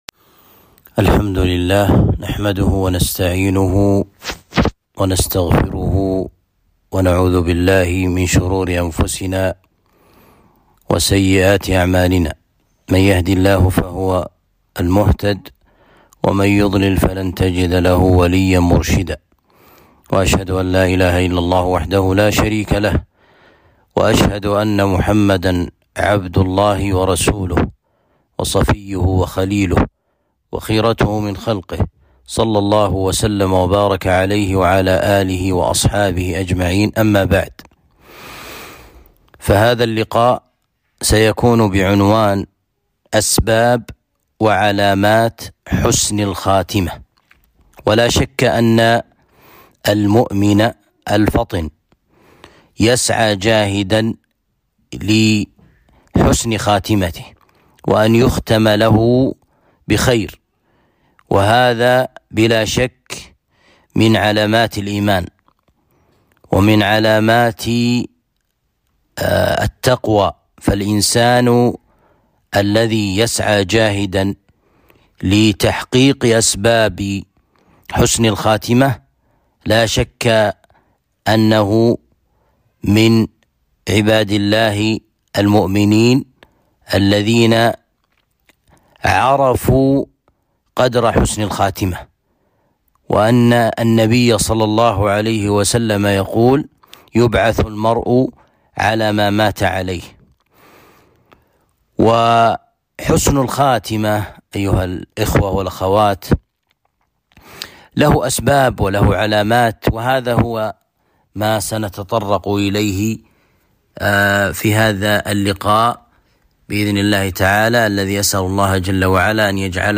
محاضرة بعنوان أسباب وعلامات حسن الخاتمة